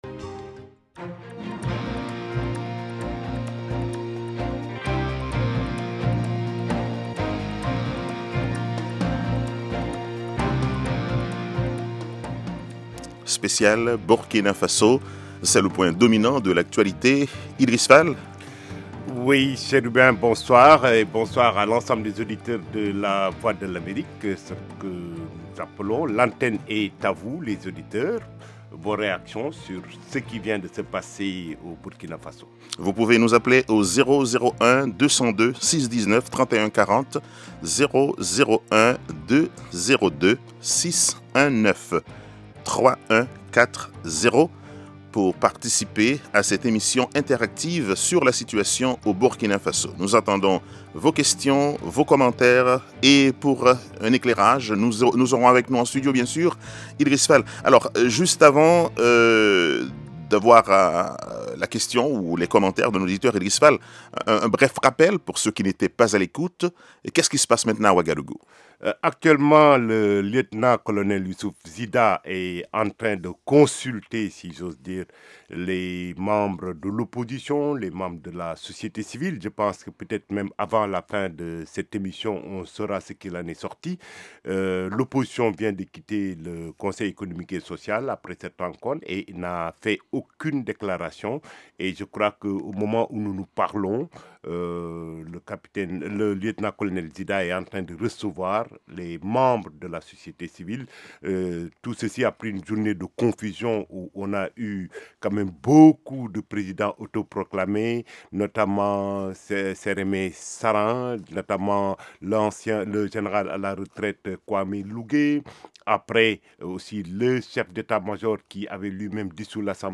Les bons vieux tubes des années 60 et 70.